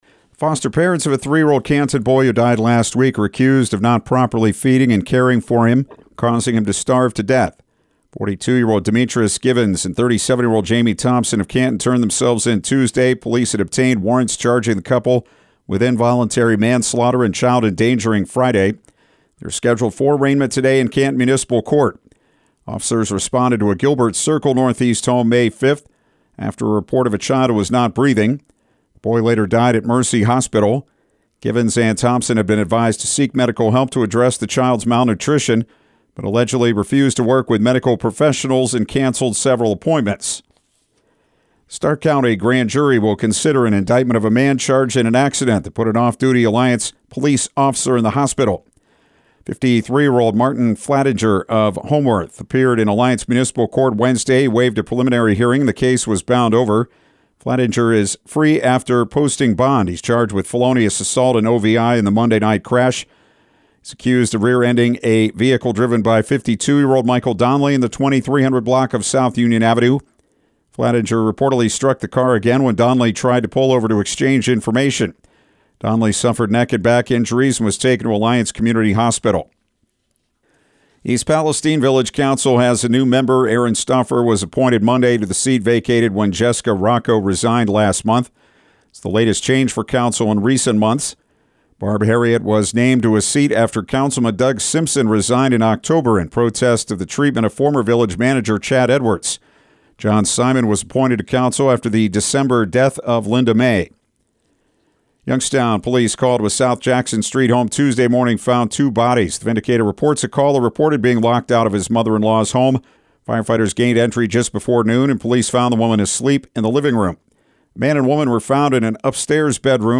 Afternoon News